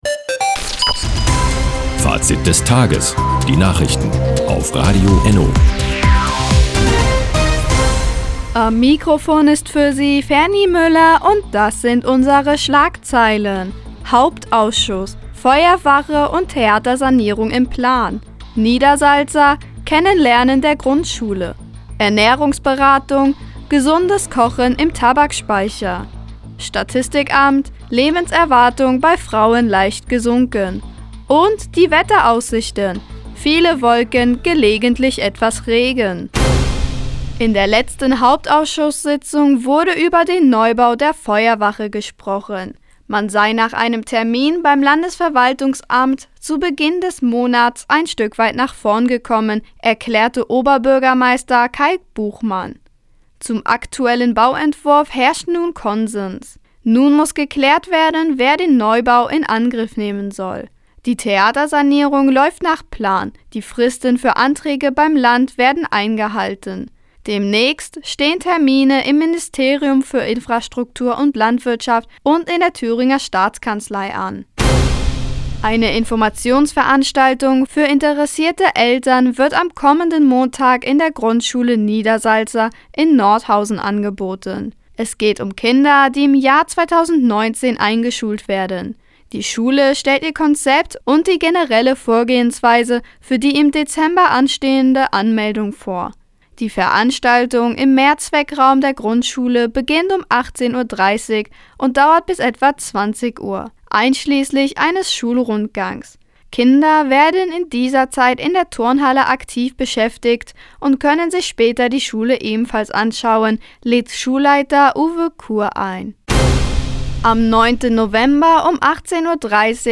Do, 16:00 Uhr 18.10.2018 Neues von Radio ENNO Fazit des Tages Seit Jahren kooperieren die Nordthüringer Online-Zeitungen und das Nordhäuser Bürgerradio ENNO. Die tägliche Nachrichtensendung ist jetzt hier zu hören...